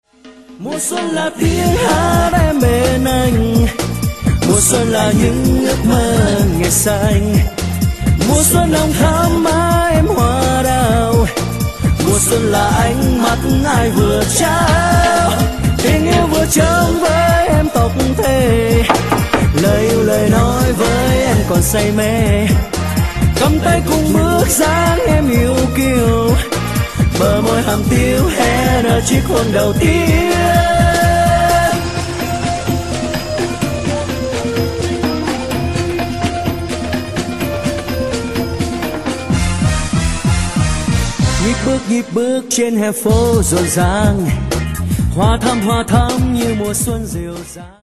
Bolero/ Trữ tình